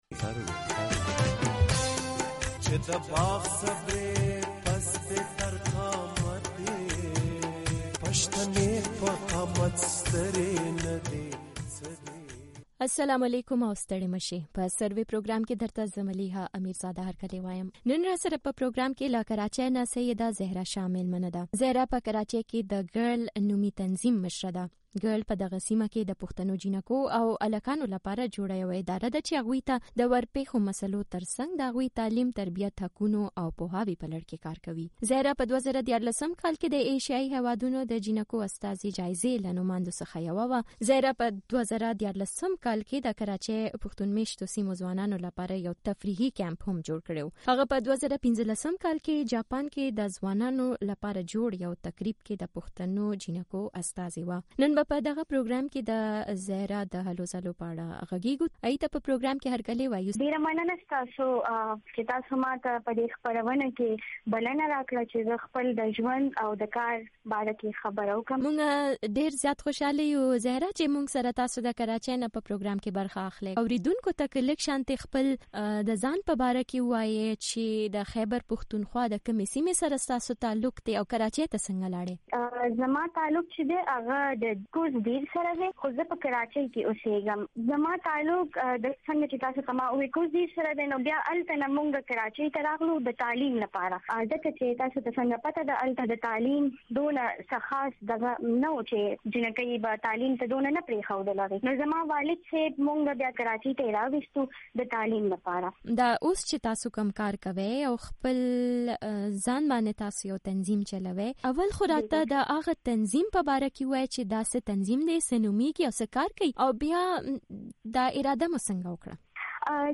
خبرې